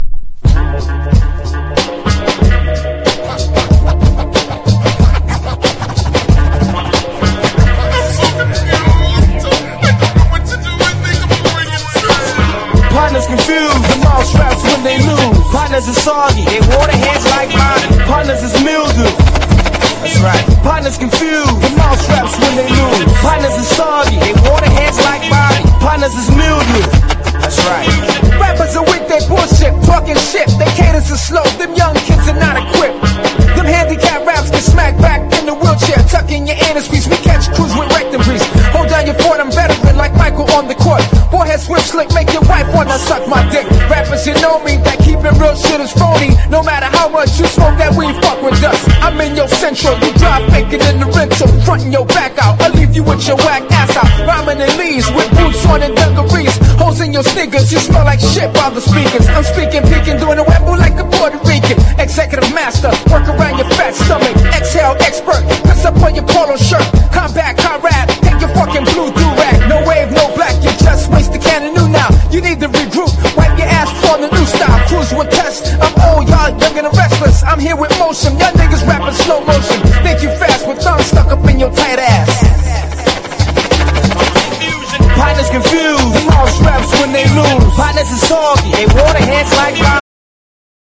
# 90’S HIPHOP# UNDERGROUND HIPHOP